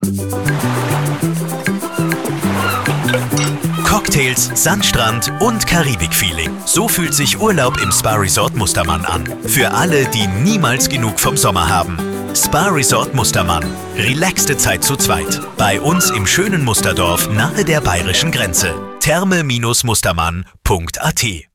Voice Clone
Radiowerbespot 03
Radiospot "Karibik"
Immer fällt eine gewisse Monotonie auf, die Betonungen wirken oft übertrieben und künstlich.